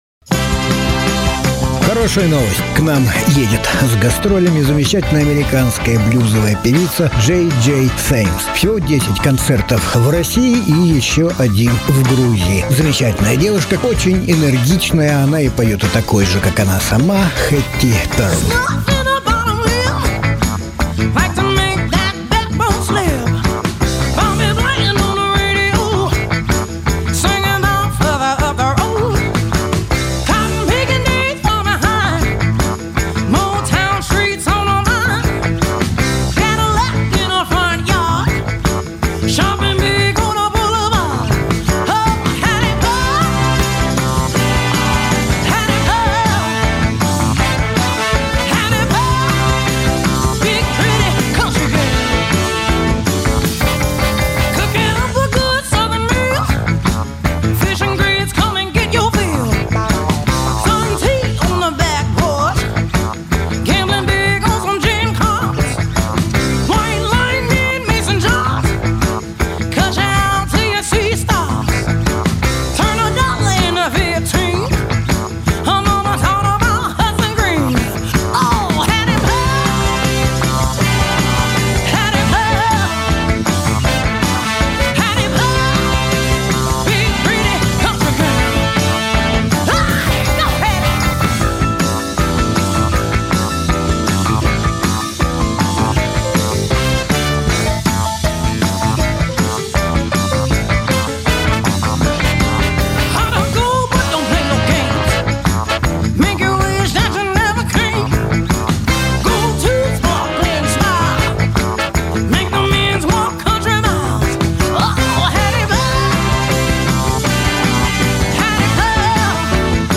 Жанр: Блюз